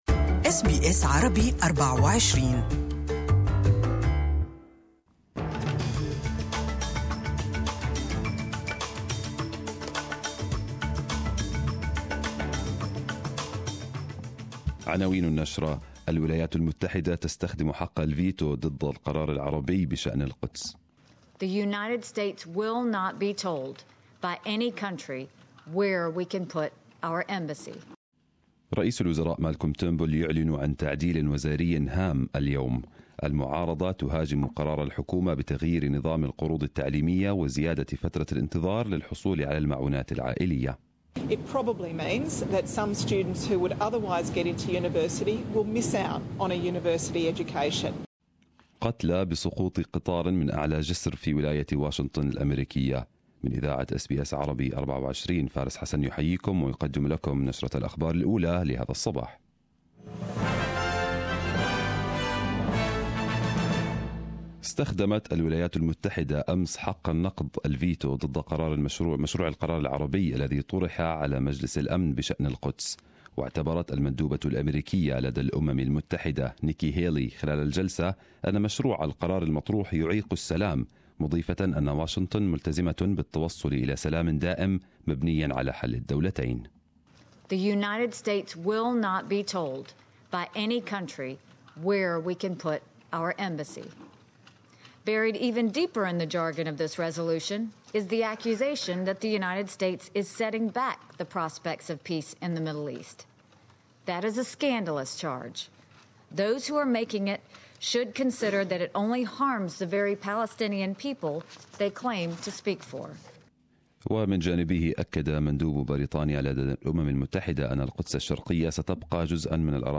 Arabic News Bulletin 19/12/2017